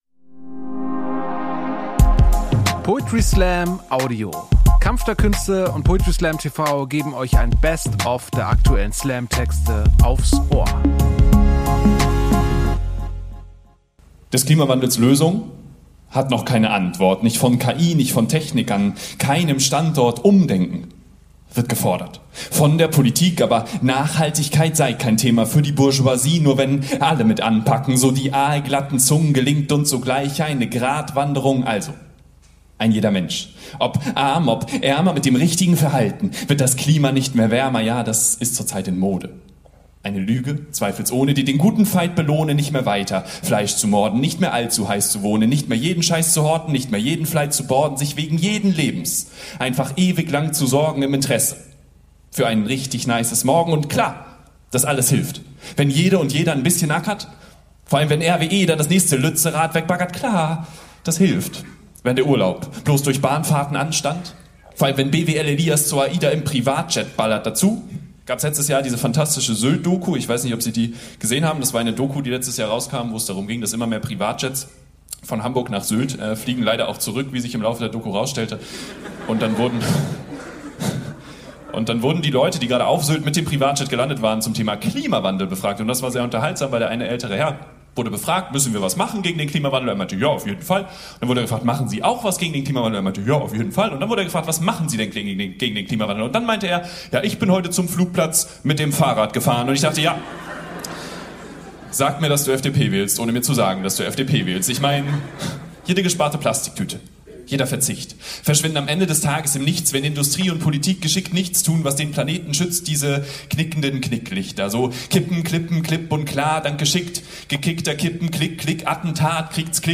Schauspielhaus, Hamburg Poetry Slam TV Website: